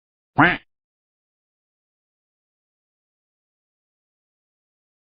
Quack Meme Sound Effect sound effects free download